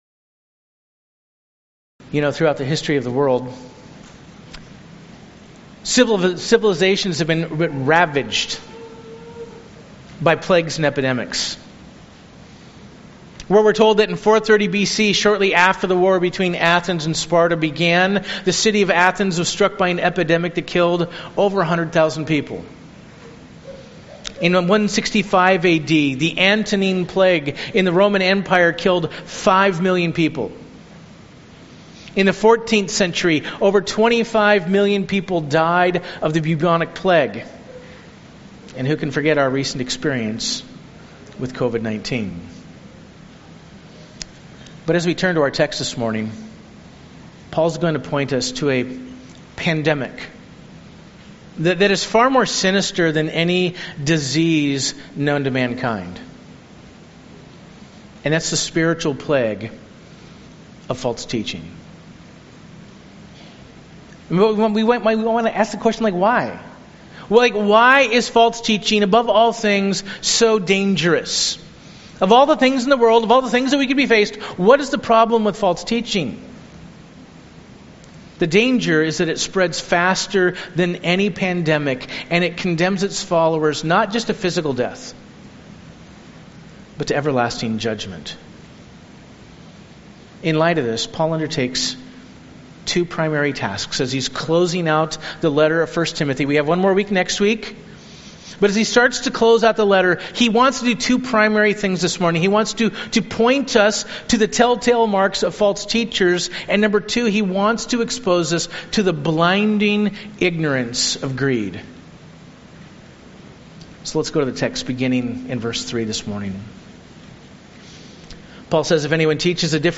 Sermon Outline: I. The Telltale Marks of False Teachers II.